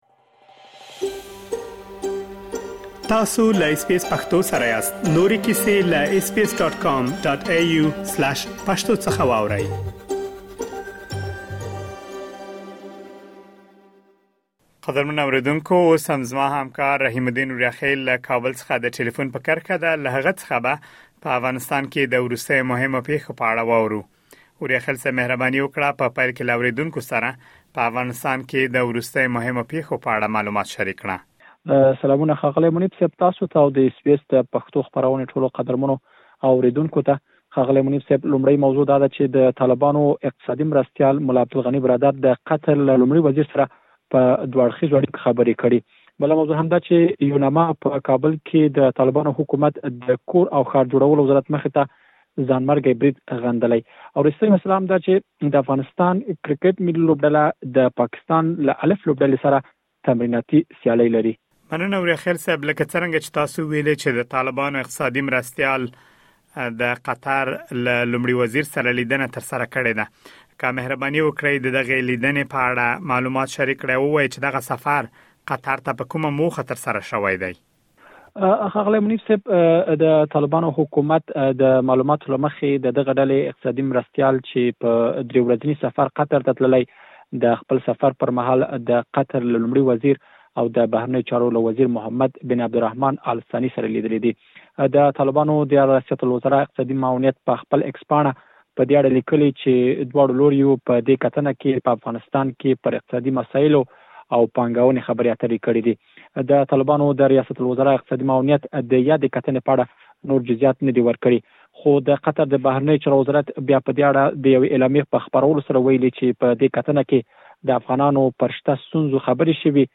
د افغانستان د وروستیو پېښو په اړه مهم معلومات په ترسره شوې مرکې کې اورېدلی شئ.